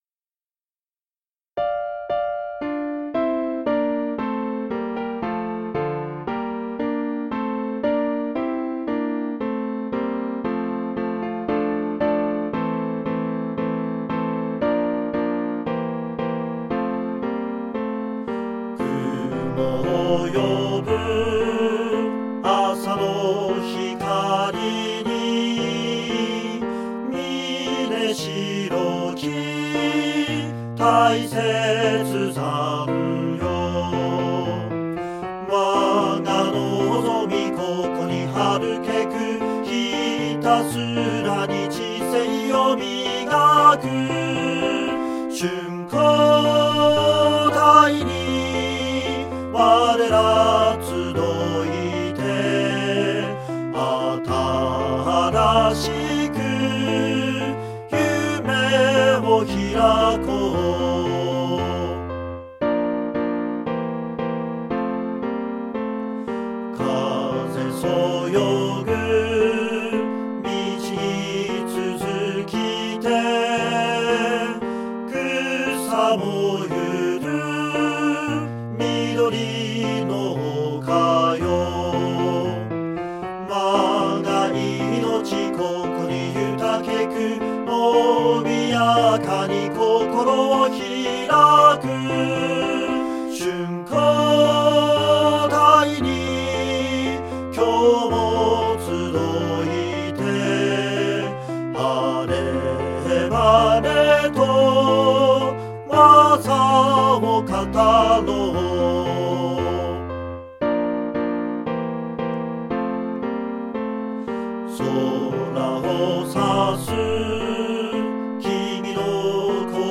校歌（歌あり）